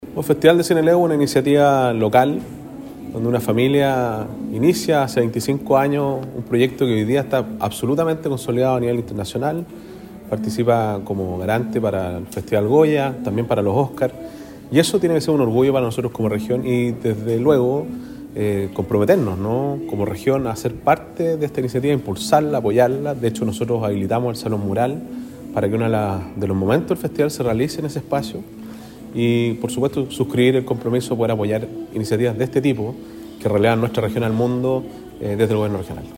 En conferencia de prensa realizada en el Hotel Araucano, en el centro penquista, se dieron a conocer los principales hitos de esta edición especial, marcada por el fortalecimiento de la industria audiovisual regional y nacional, con el apoyo tanto del sector público como de la empresa privada.